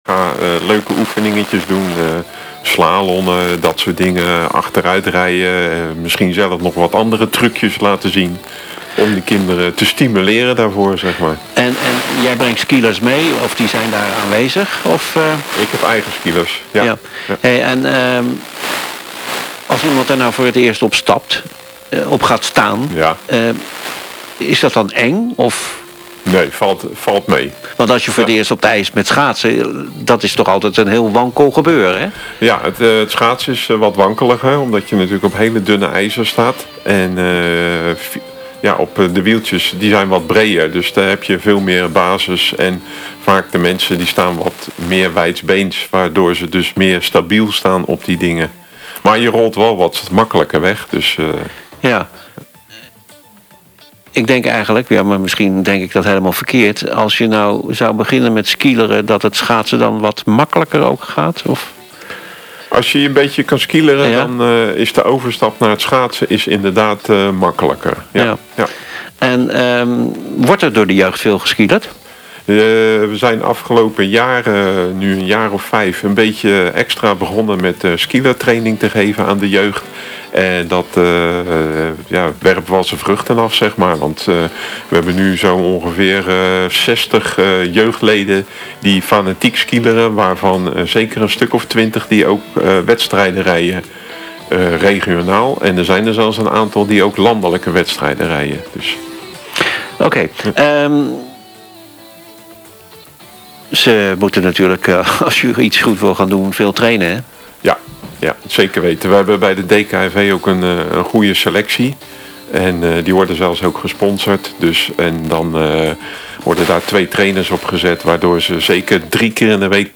Een interview over Onze Spelen, de eerste editie van de Delfse zomerspelen.